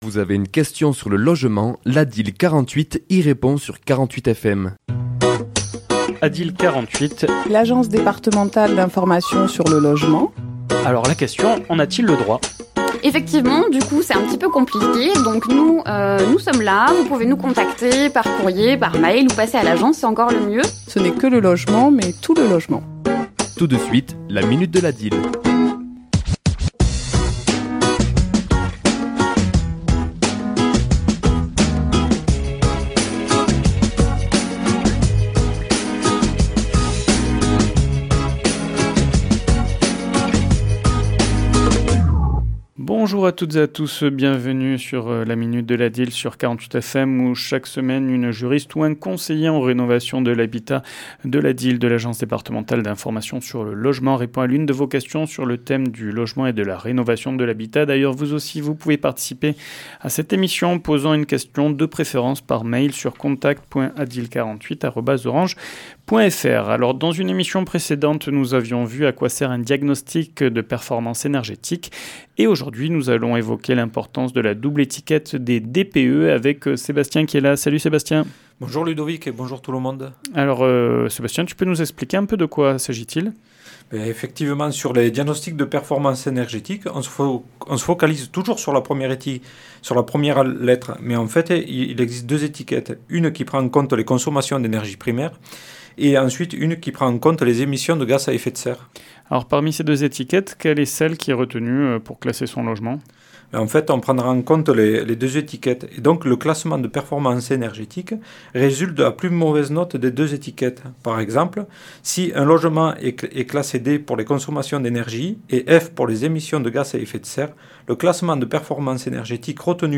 Chronique diffusée le mardi 18 mars à 11h et 17h10